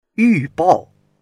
yu4bao4.mp3